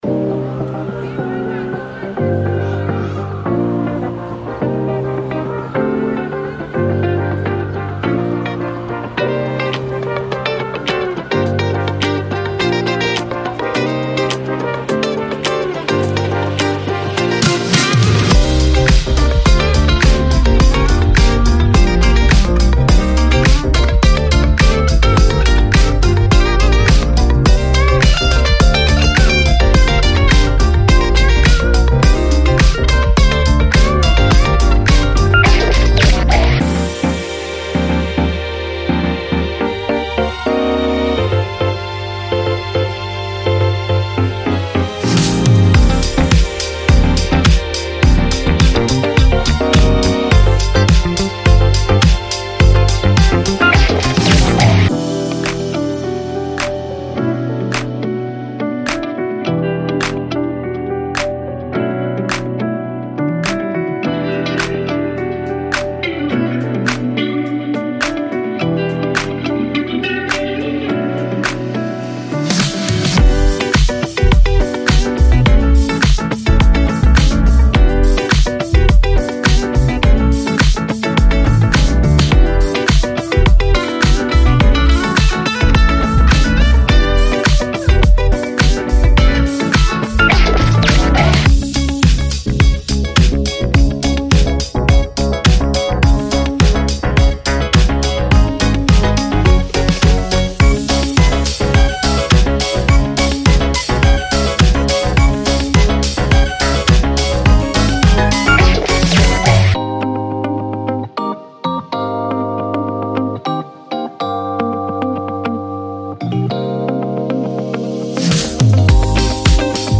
所有内容均经过精心混音和均衡处理，以实现最佳音色平衡，同时预留充足的动态余量，方便后续处理和最终母带制作